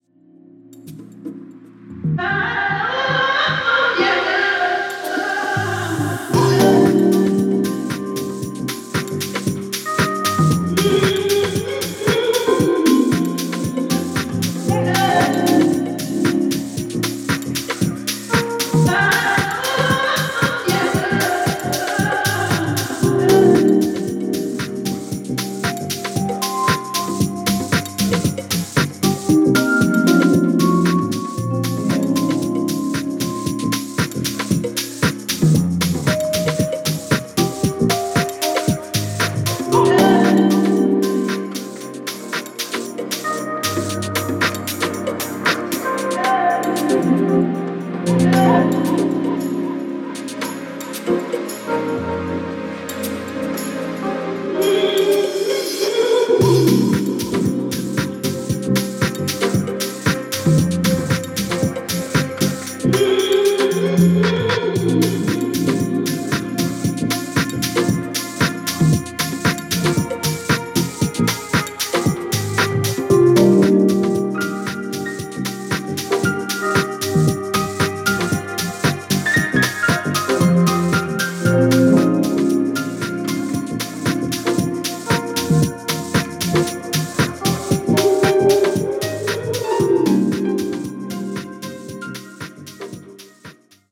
Dead Stock（試聴録りしました）